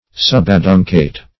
Subaduncate \Sub`a*dun"cate\, a.